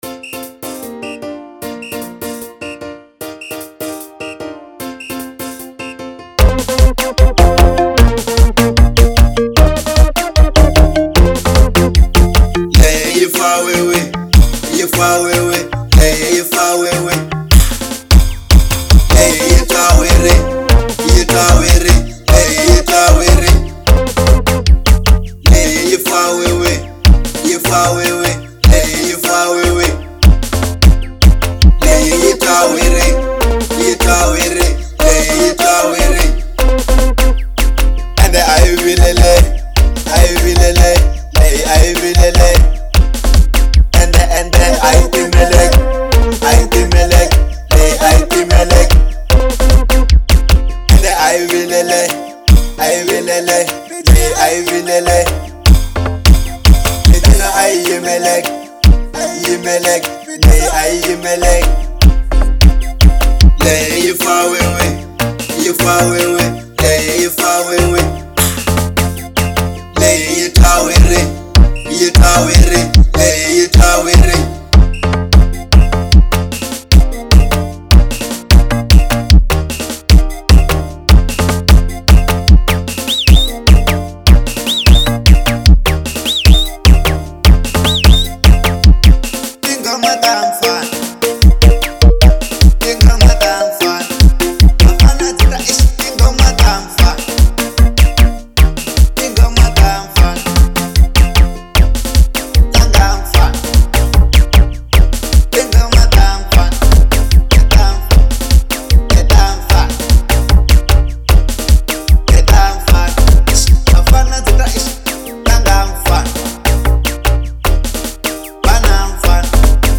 03:12 Genre : Xitsonga Size